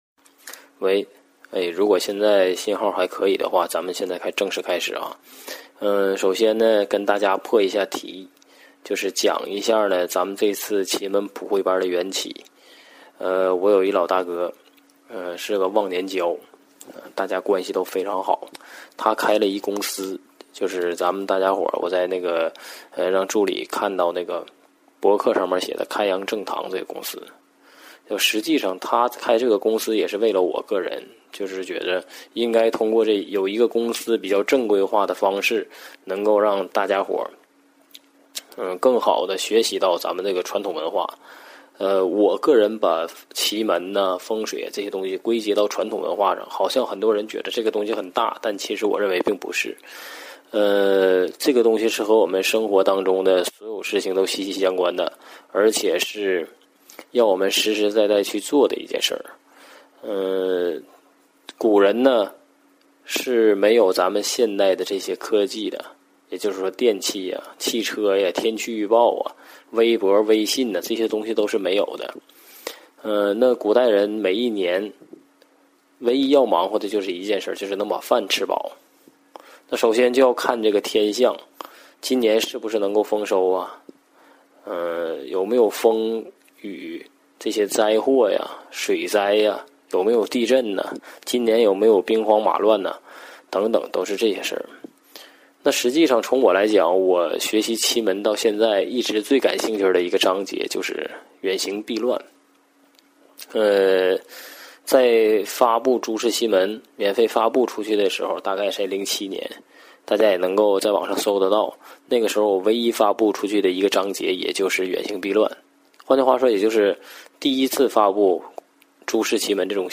《奇门遁甲普惠班》4天课程录音及资料百度网盘分享